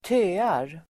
Ladda ner uttalet
Uttal: [²t'ö:ar]